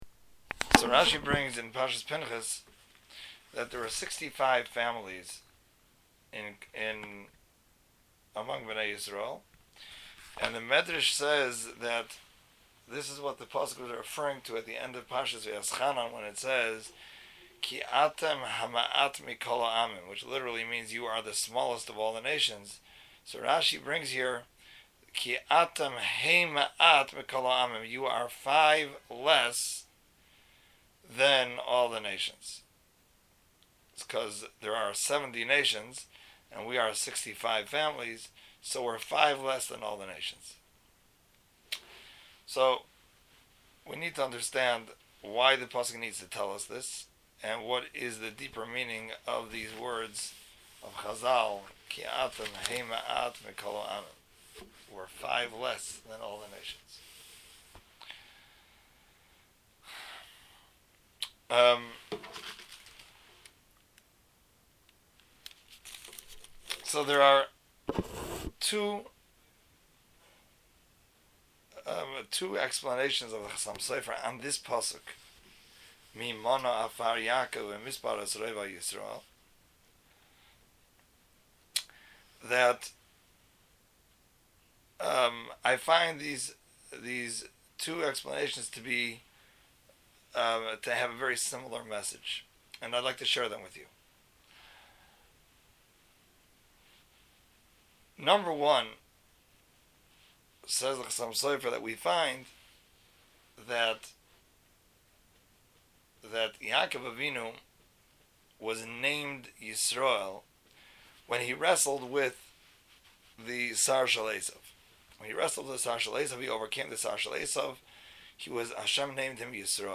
Balak Drasha 5775 B